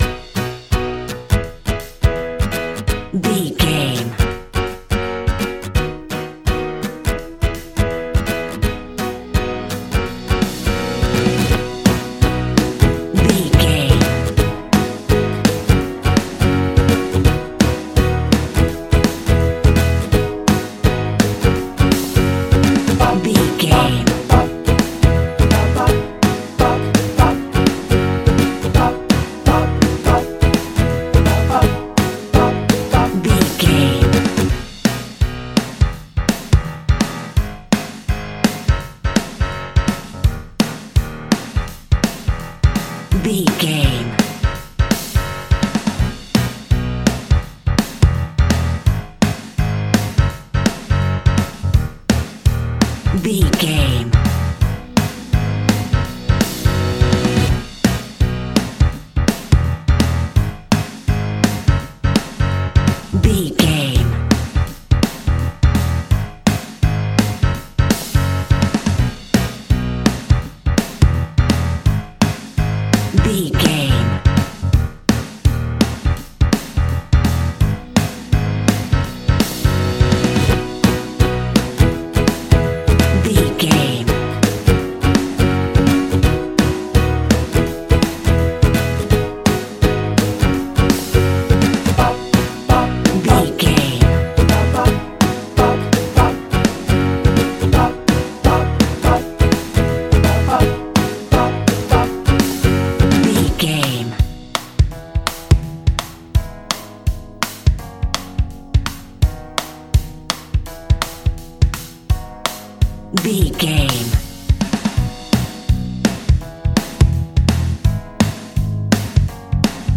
Ionian/Major
pop rock
indie pop
energetic
uplifting
cheesy
upbeat
groovy
guitars
bass
drums
piano
organ